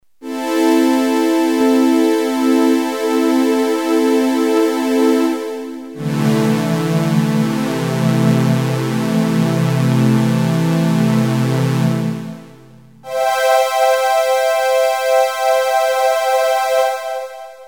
edit EFFECT a digital chorus is included with variable rate/depth.
HEAR digital CHORUS